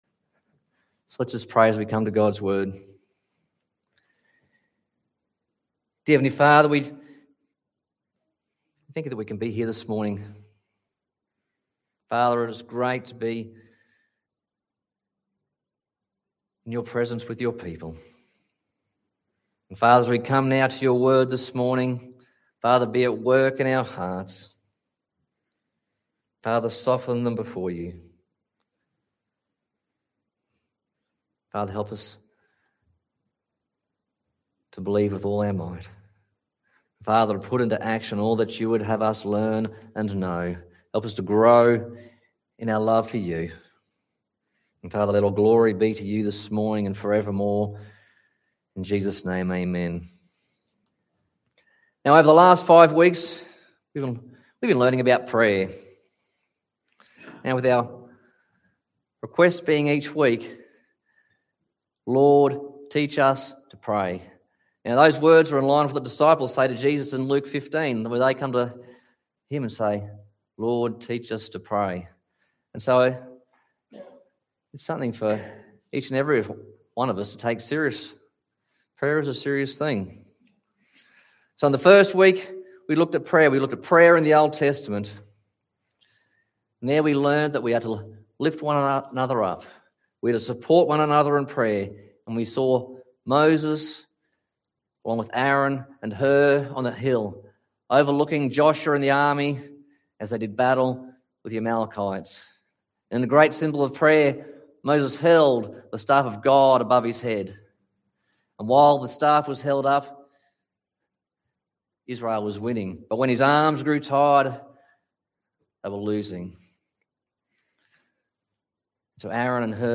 Lord teach us to pray Passage: Song of Songs 2:14-15, Acts 9:1-19 Service Type: Sunday Morning « Prayer is an Act of Faith Jesus